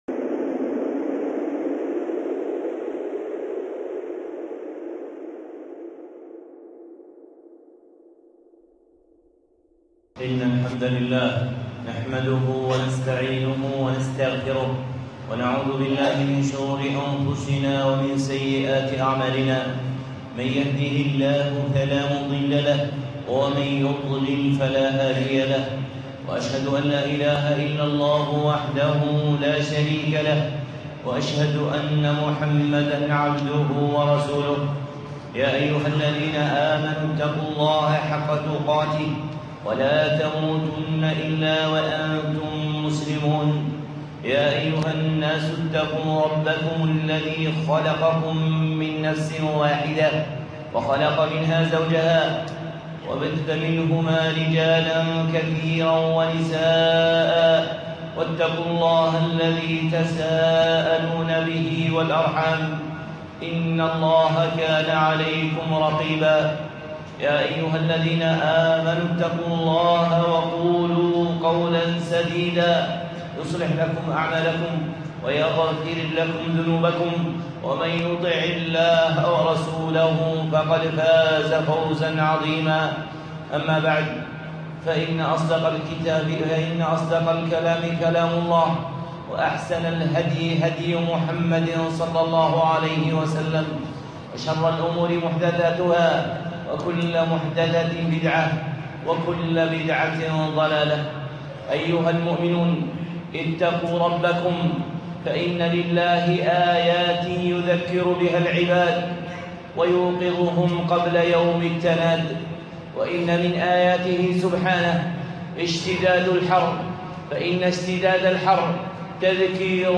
خطبة (الحر القادم) الشيخ صالح العصيمي